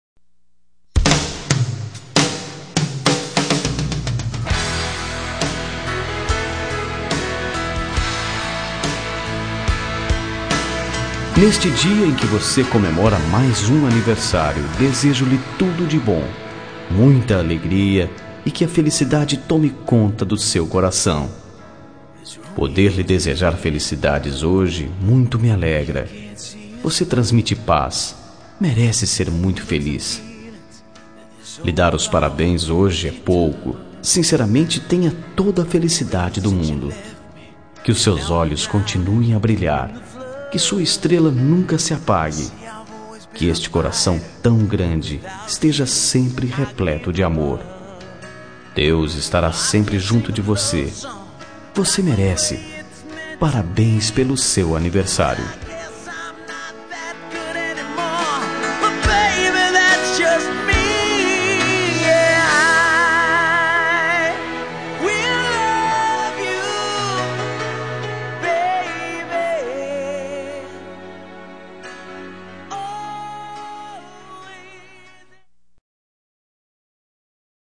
Telemensagem Aniversário de Amiga – Voz Masculina – Cód: 1590